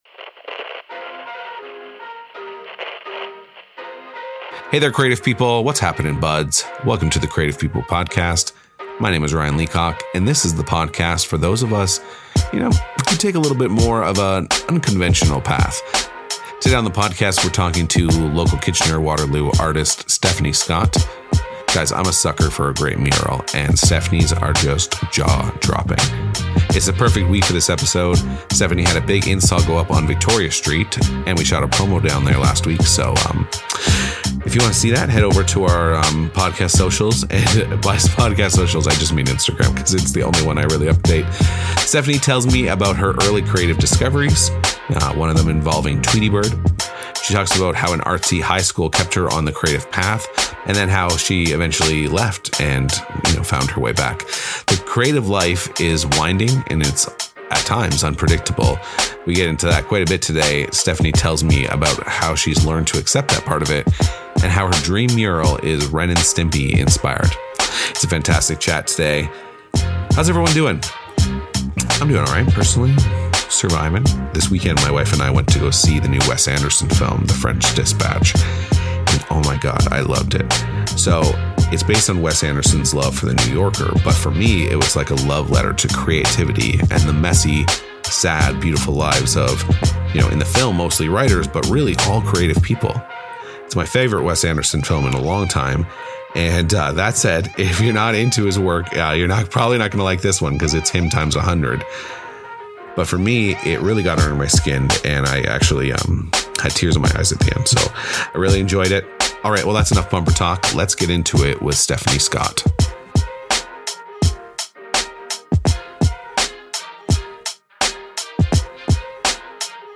This week on the podcast we're back in the studio with artist